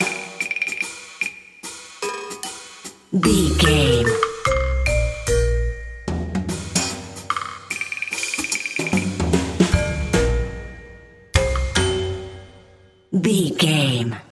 Ionian/Major
B♭
drums
percussion
double bass
silly
circus
goofy
comical
cheerful
perky
Light hearted
quirky